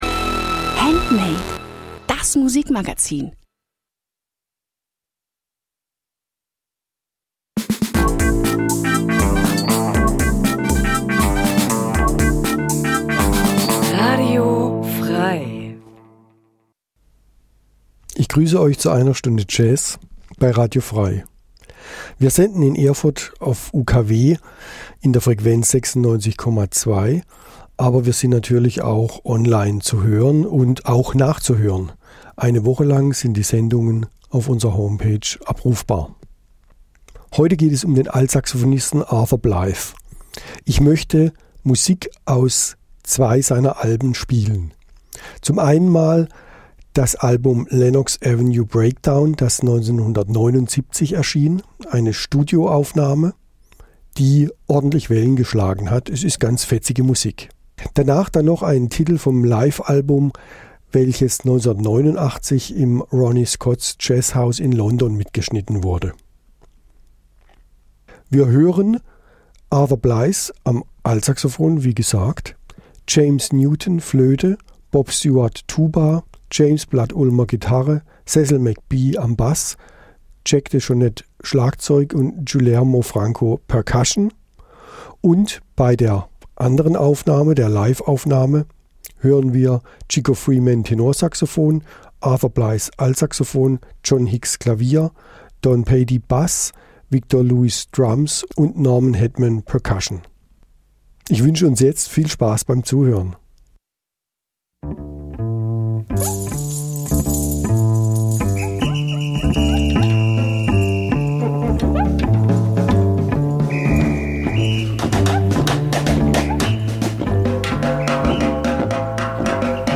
Eine Stunde Jazz Dein Browser kann kein HTML5-Audio.